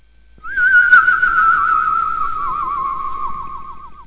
1 channel
spacey.mp3